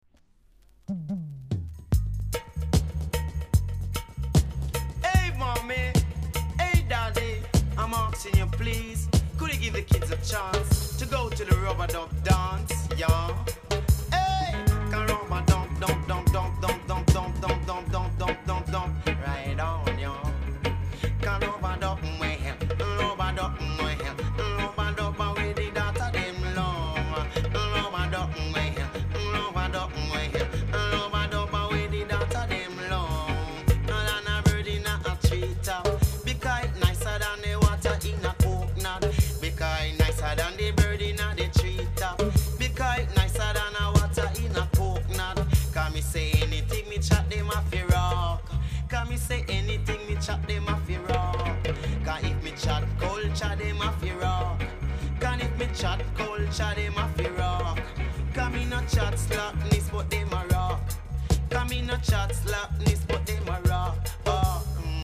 ※小さなチリノイズが少しあります。
コメント KILLER DEEJAY!!